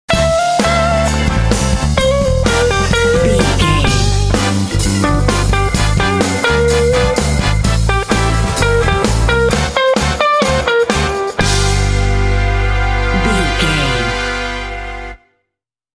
Aeolian/Minor
intense
driving
energetic
groovy
funky
electric guitar
electric organ
bass guitar
drums
brass
Funk
blues